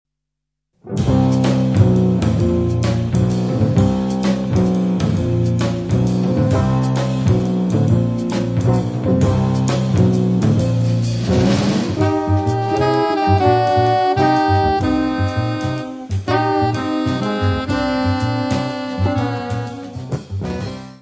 guitarra
saxofone alto e saxofone soprano
trombone
bateria
contrabaixo.
Área:  Jazz / Blues